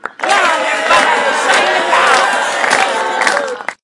人群欢呼拍手
用内置麦克风记录。 在这个版本中可以听到一大群人在庆祝欢呼。
Tag: 庆典 欢呼 兴奋 干杯 拍手 喜悦 快乐 有活力 人声